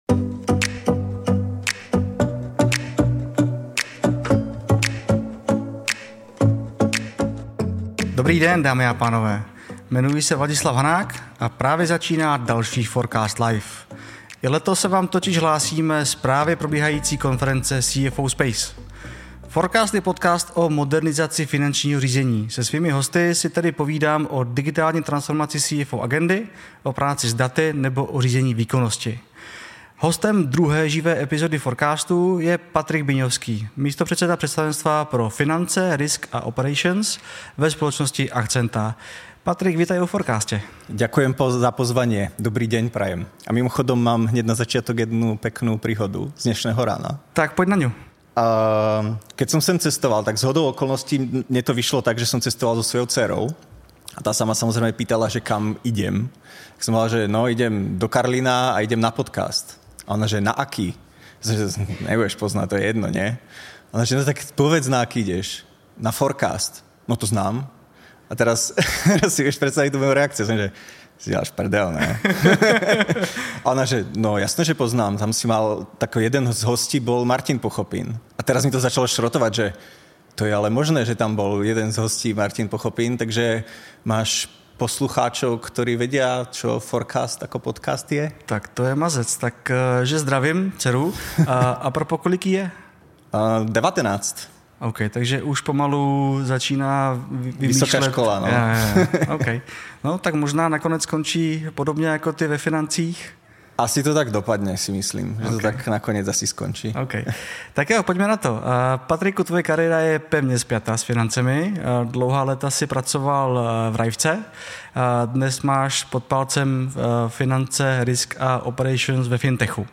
živě natočené epizody podcastu FORECAST